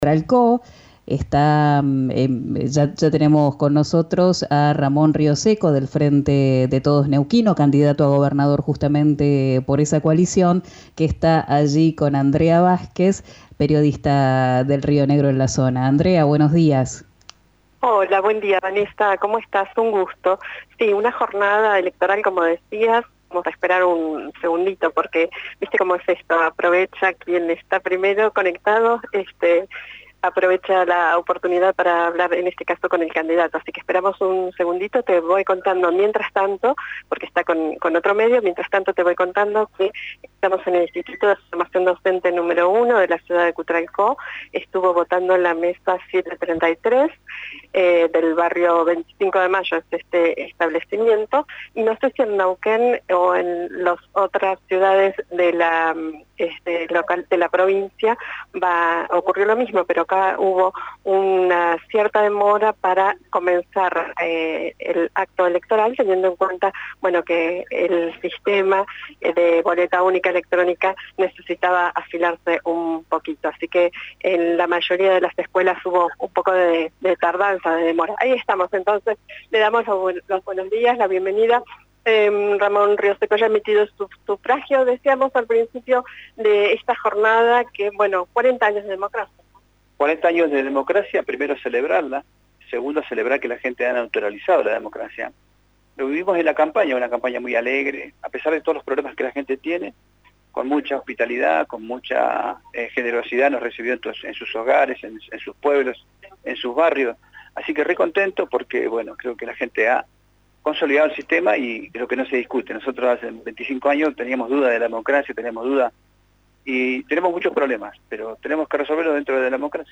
Esta mañana, en la mesa N° 733 del IFD N° 1, Rioseco hizo fila y luego de sufragar habló con RÍO NEGRO RADIO.
Escuchá a Ramón Rioseco en RÍO NEGRO RADIO: